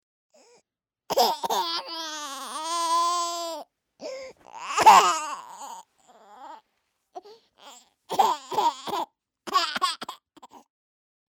دانلود افکت صوتی گریه نوزاد تازه متولد شده
دانلود صدای گریه نوزاد mp3
downloadbabycrysound.mp3